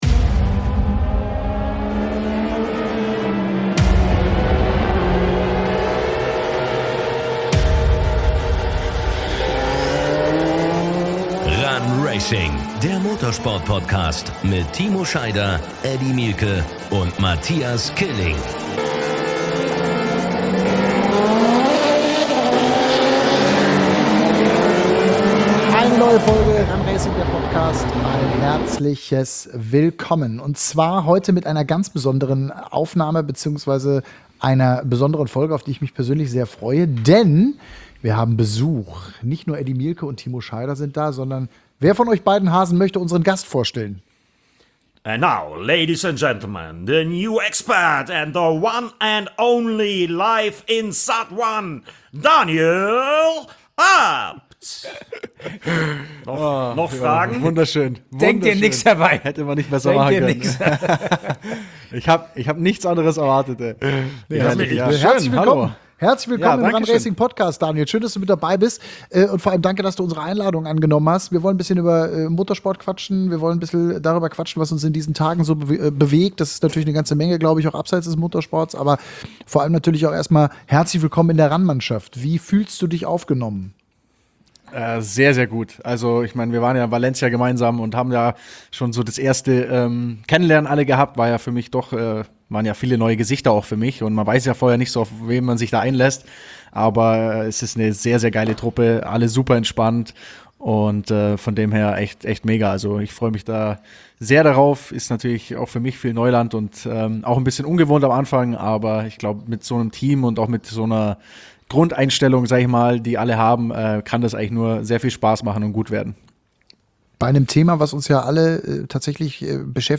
Drei Boys und Daniel Abt im Gespräch